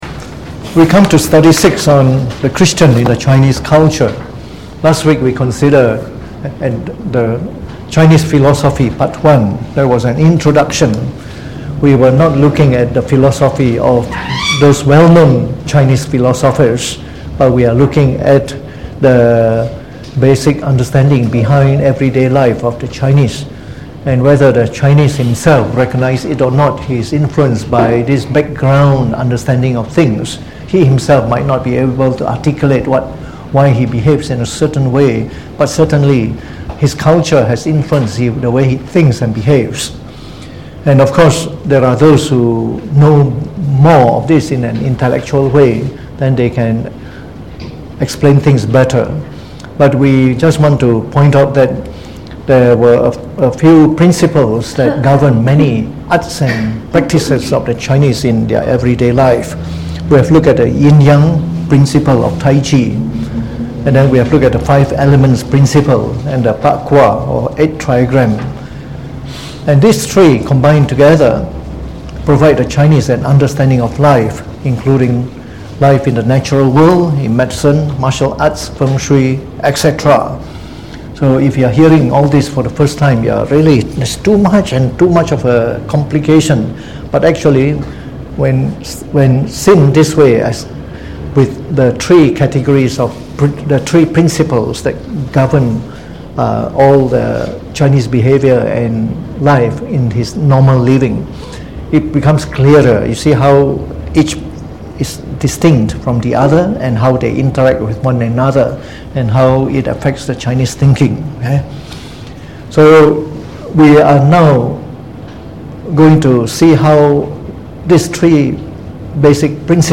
Delivered on the 25th of September 2019 during the Bible Study, from the series on The Chinese Religion.